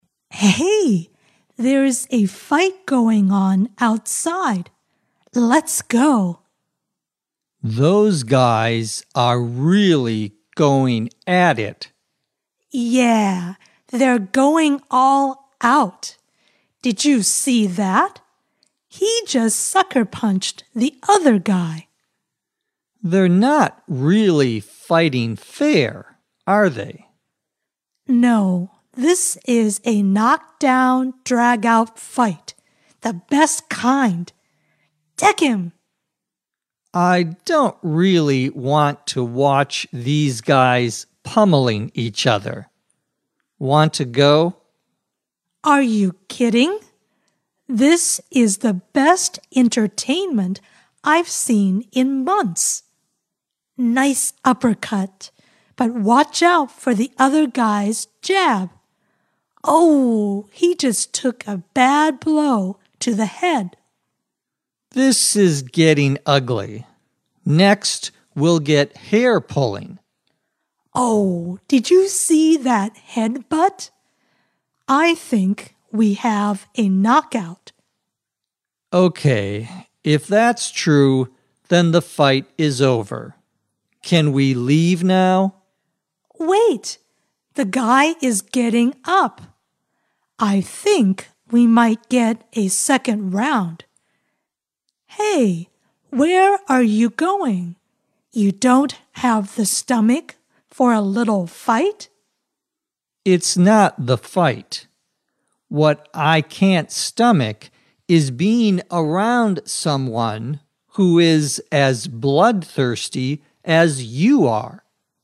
地道美语听力练习:围观别人打架损不损害社会公德?